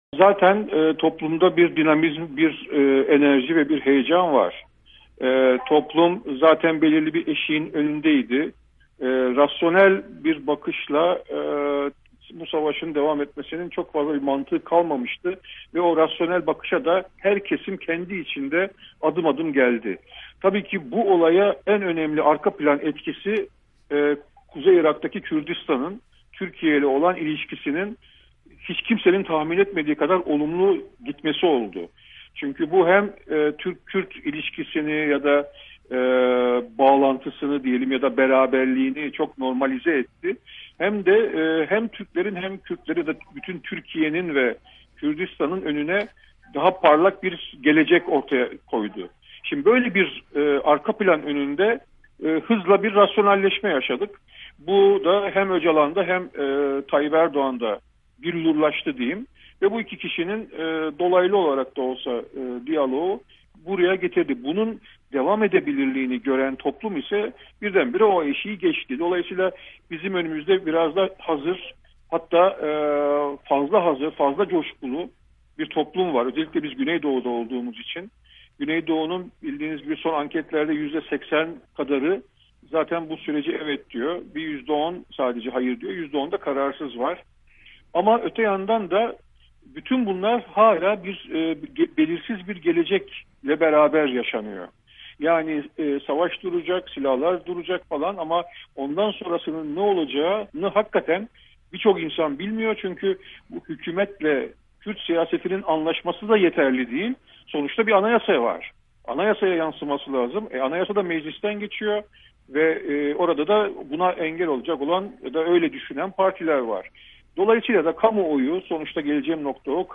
Etyen Mahçupyan'la söyleşi